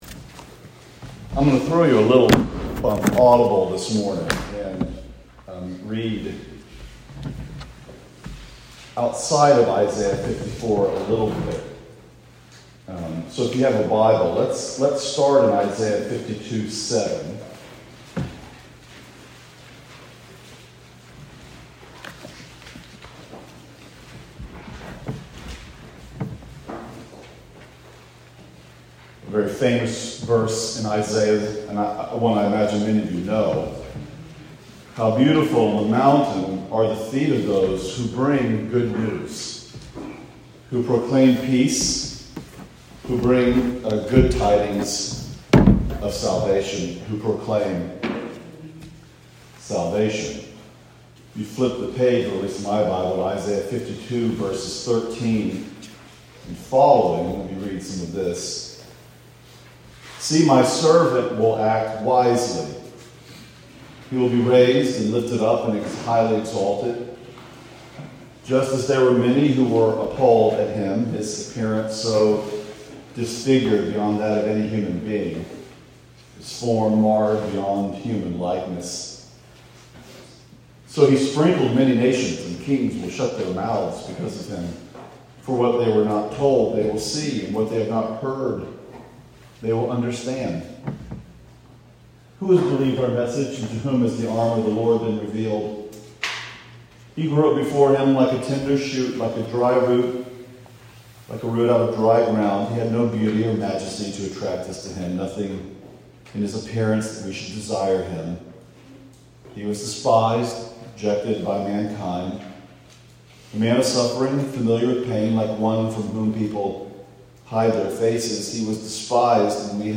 2022 Pine Mountain Church Retreat